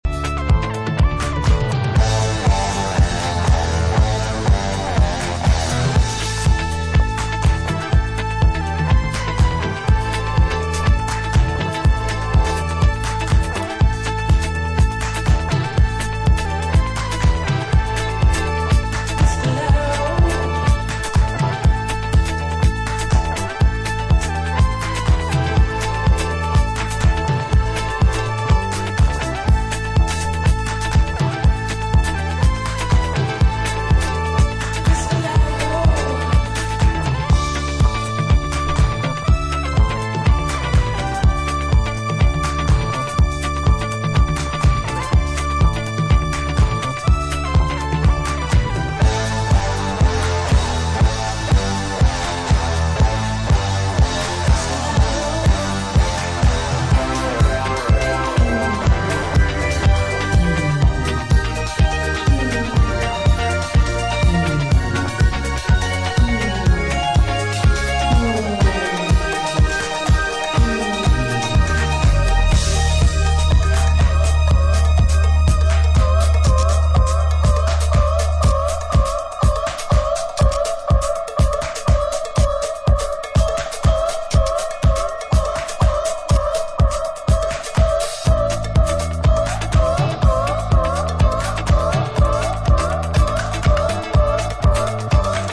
Another compilation of disco hits and oddities
funk is on Disco